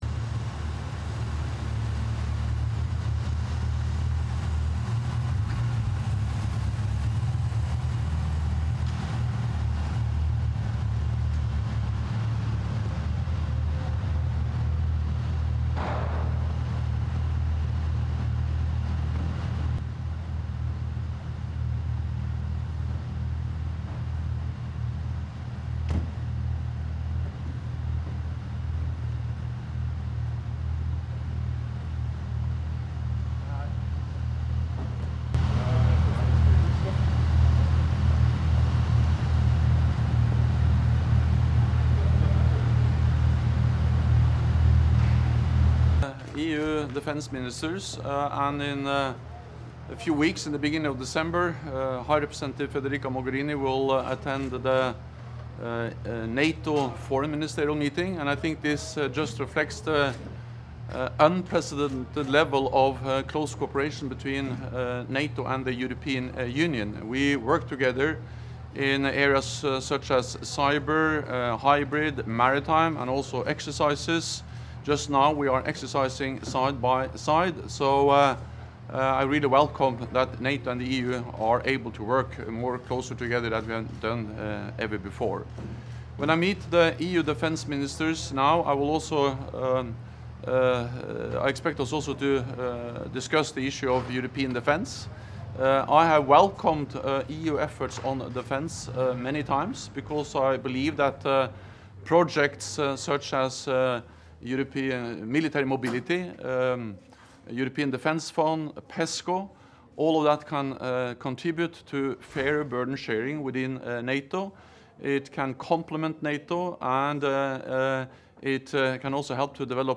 Doorstep statement
by NATO Secretary General Jens Stoltenberg prior to the European Union Foreign Affairs Council meeting